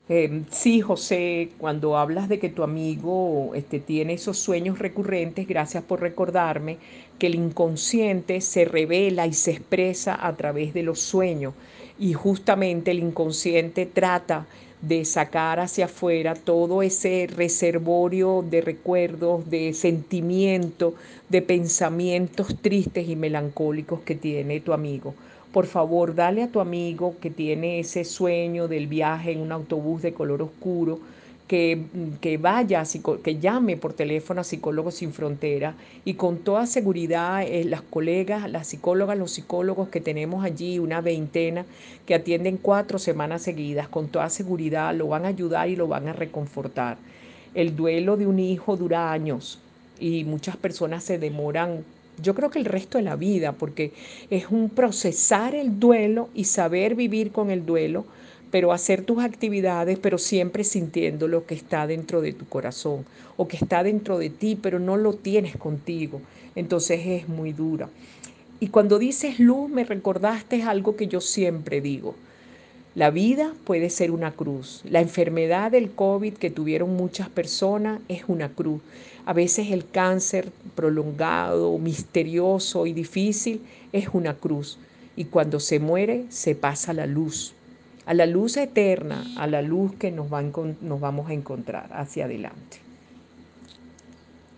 Ronda de preguntas